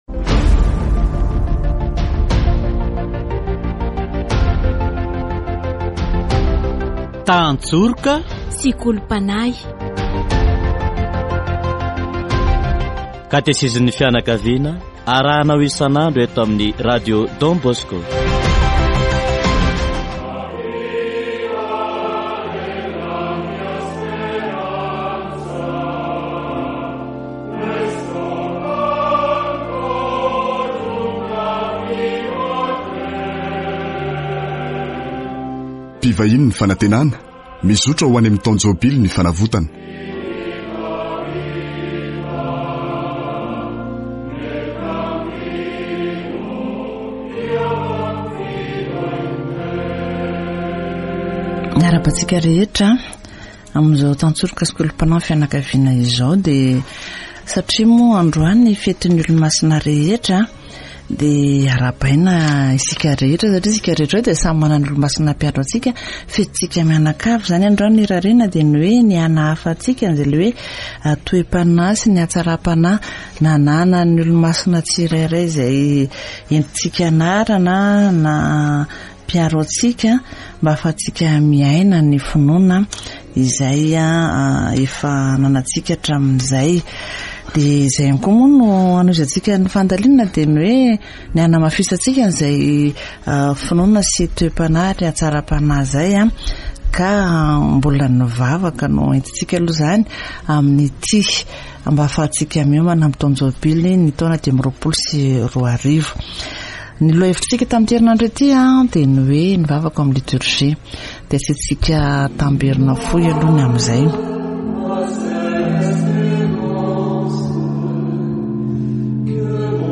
Catechesis on Prayer in the Liturgy